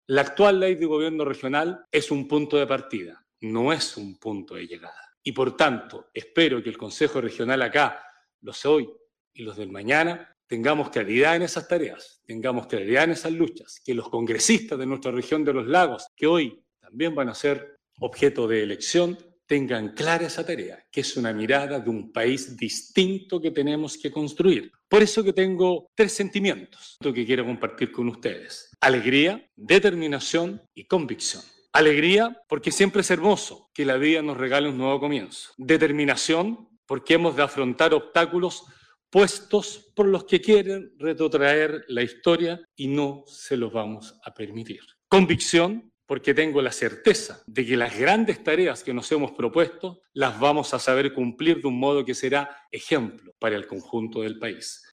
Vallespín, además señaló que, con este acto republicano se inicia una nueva mirada a lo que será un país distinto: